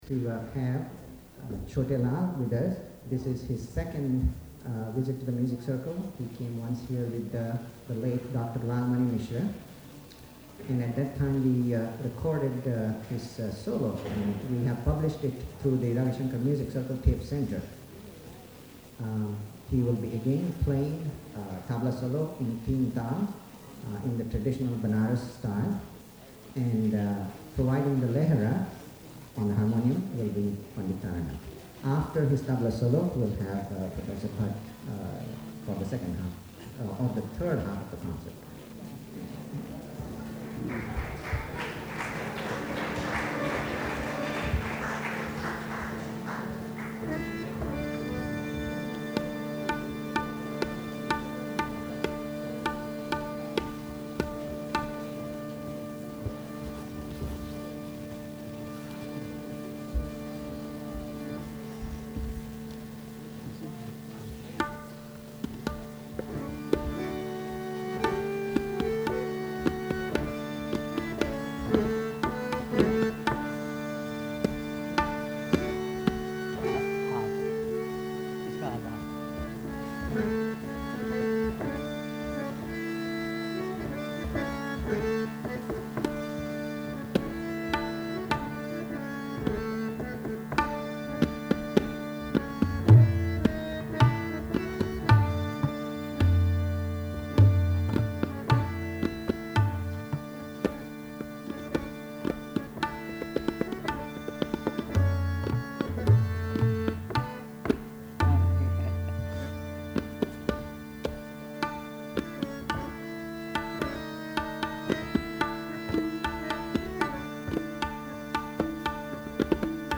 Tabla solo à Pasadena en 1980 alt : test.mp3